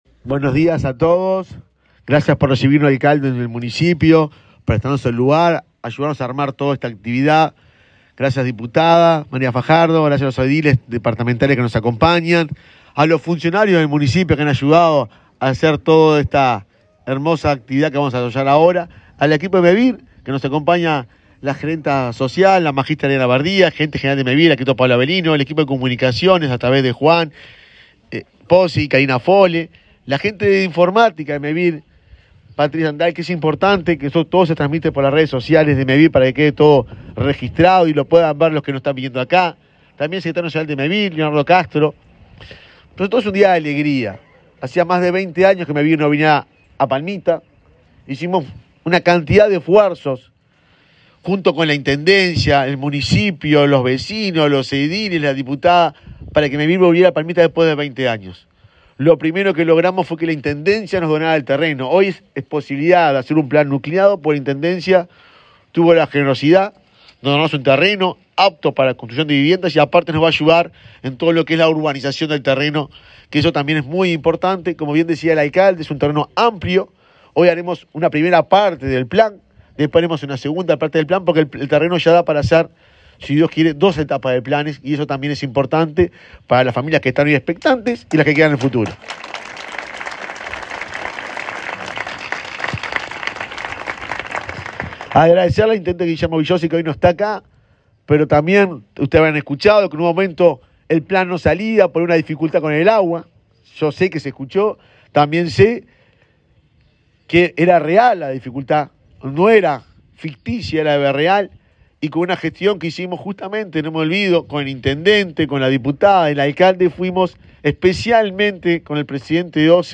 Palabras del presidente de Mevir, Juan Pablo Delgado
El presidente de Mevir, Juan Pablo Delgado, participó en el sorteo de un plan de 55 viviendas nucleadas para la localidad de Palmitas.